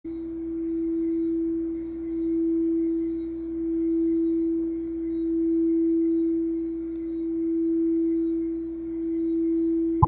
Silver Tibetan Meditation Singing Bowl – 5″D
Musical Note: D